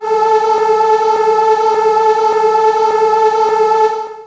55be-syn14-a3.aif